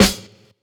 Medicated Snare 33.wav